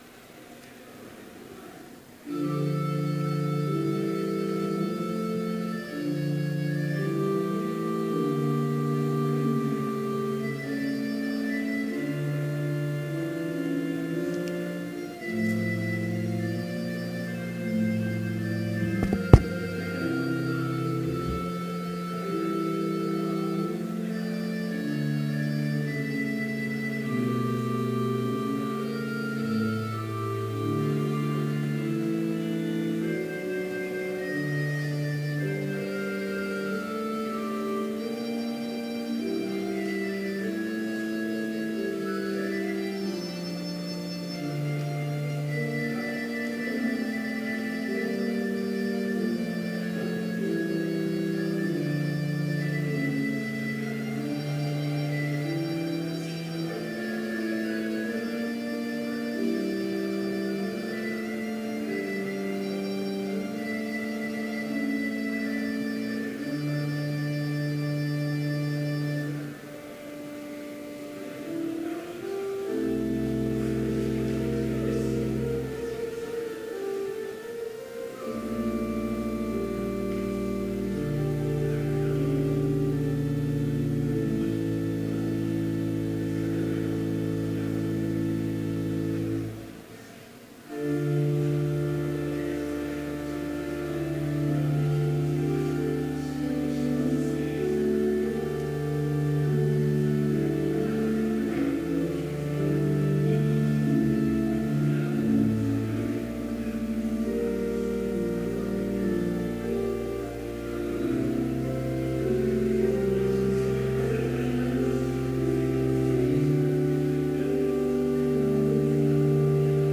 Complete service audio for Chapel - April 3, 2017
Watch Listen Complete Service Audio file: Complete Service Sermon Only Audio file: Sermon Only Order of Service Prelude Hymn 289, vv. 1 - 4, Join All the Glorious Names Reading: 1 Timothy 2:4-6 Devotion Prayer Hymn 289, vv. 5 - 7, Jesus, my great High Priest… Blessing Postlude Scripture 1 Timothy 2:4-6 God desires all men to be saved and to come to the knowledge of the truth.